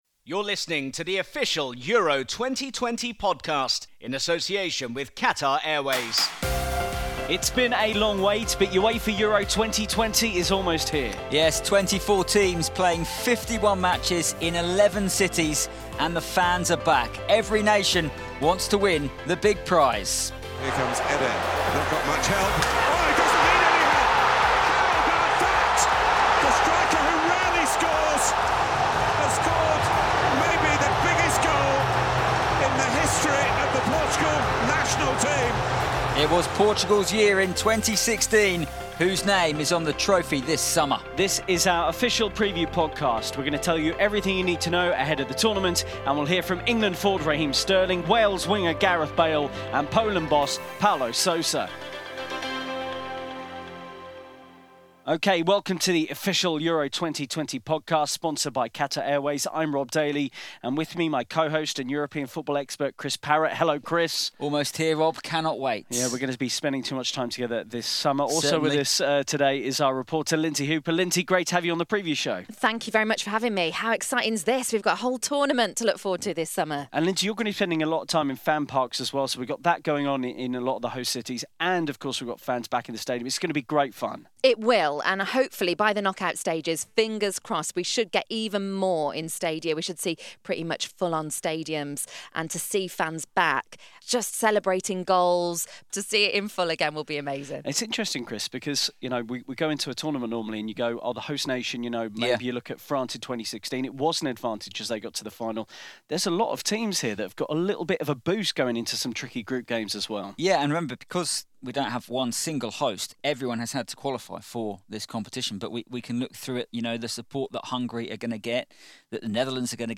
We hear from Raheem Sterling, Gareth Southgate, Gareth Bale, Steve Clarke and Paulo Sousa.